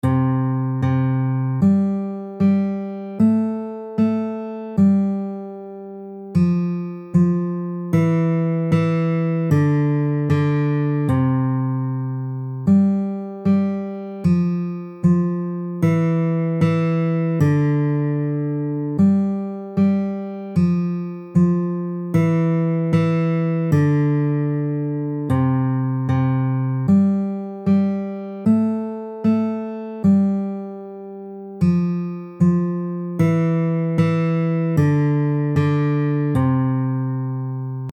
※きらきら星は、１８世紀末フランスで流行した民謡です。